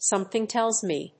アクセントsómething télls me